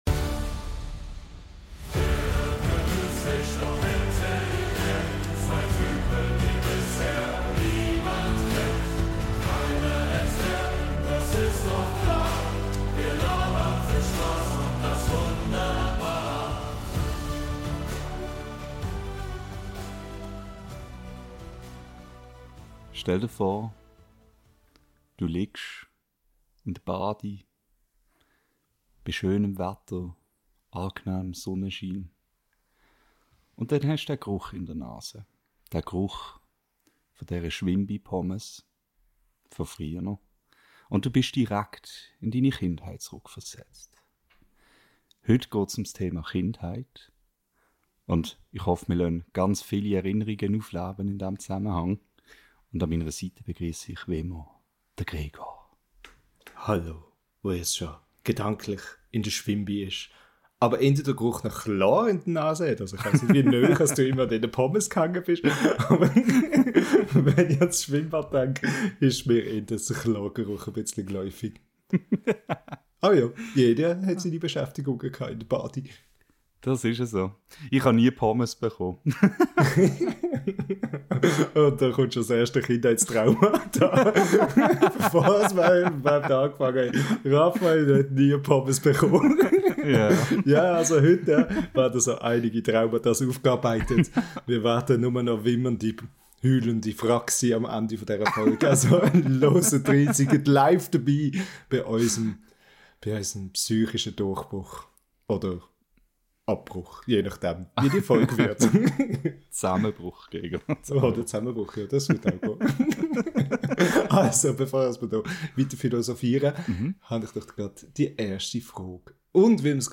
In dieser Folge unseres schweizerdeutschen Podcasts tauchen wir tief in unsere Kindheitserinnerungen ein – mit all ihren lustigen, absurden und vielleicht sogar leicht traumatischen Momenten. Hört rein, wenn die dummen Kinder von damals versuchen, ihre Vergangenheit zu rekonstruieren und alten Kindheitstraumata auf den Grund zu gehen.